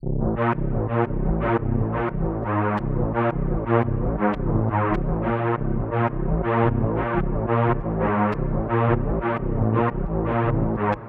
Convoy Strings 01.wav